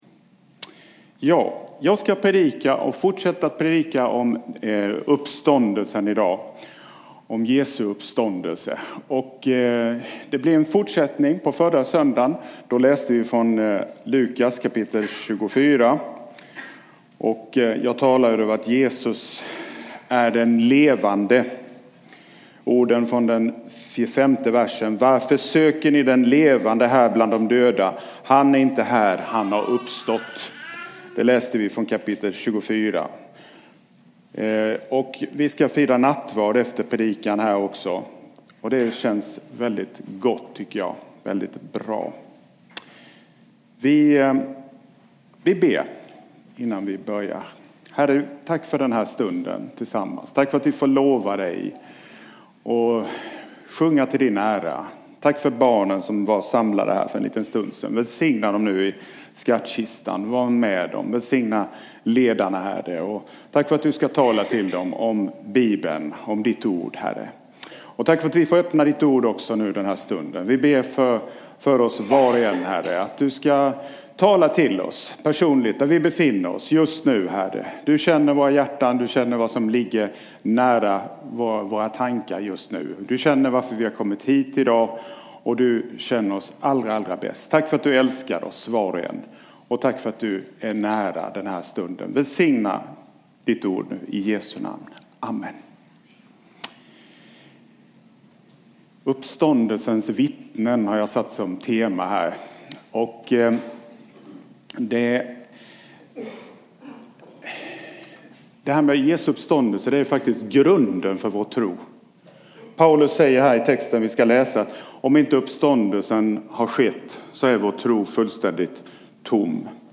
A predikan from the tema "Fristående VT 2018."